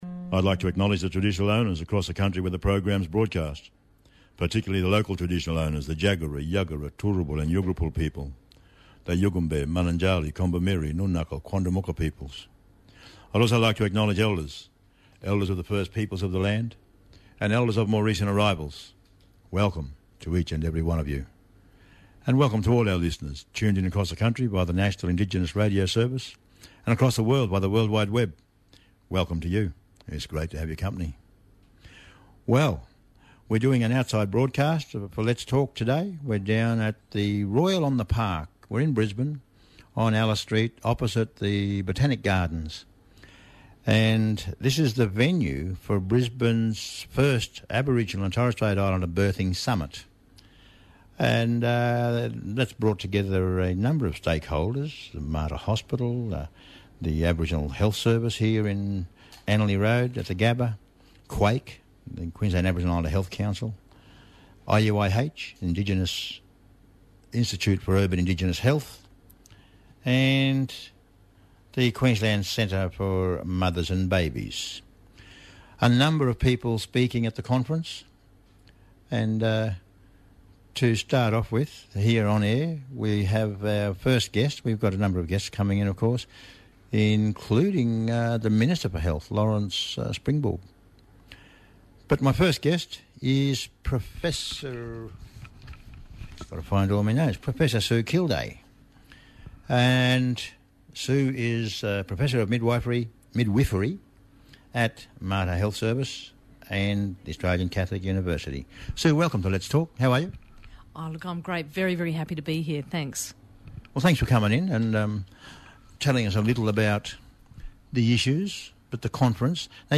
Special broadcast from the Qld Aboriginal and Torres Strait Islander Birthing Summit held in Brisbane.